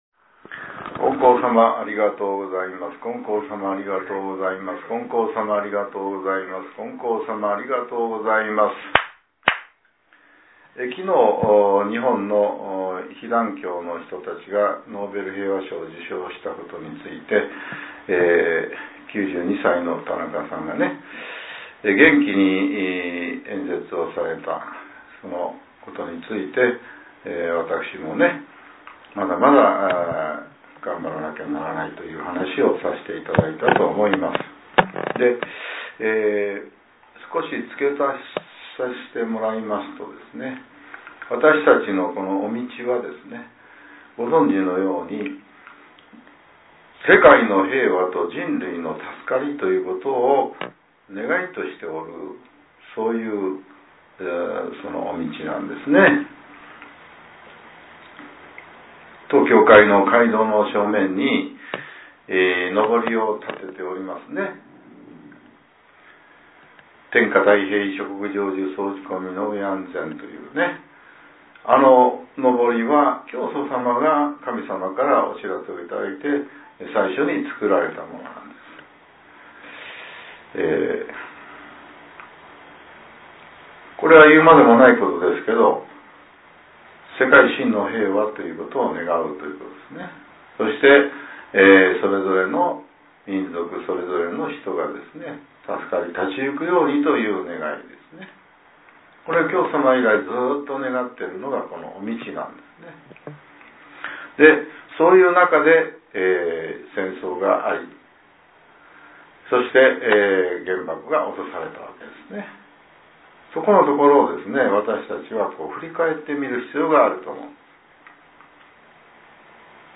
令和６年１２月１２日（朝）のお話が、音声ブログとして更新されています。